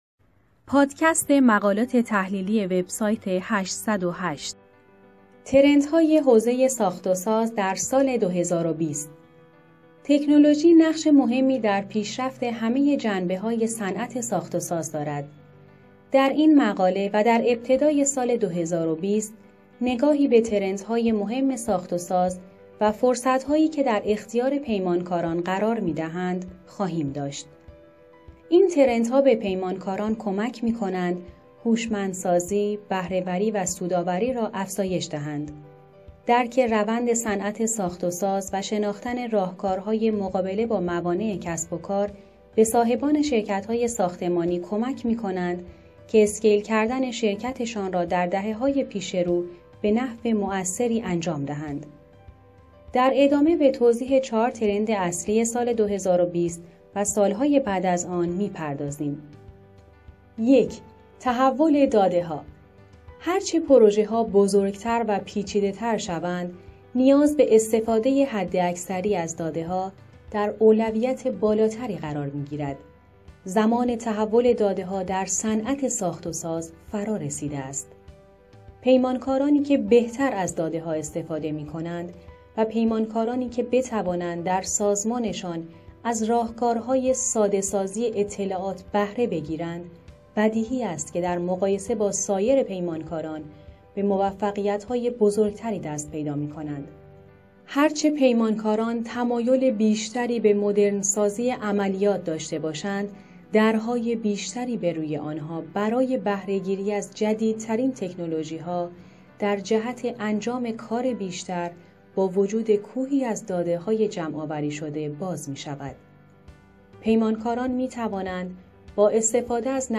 برای آشنایی با نحوه استفاده از اپلیکیشن 808 پلاس و همچنین شنیدن پادکست صوتی مقالات تحلیلی، فیلم زیر را مشاهده کنید: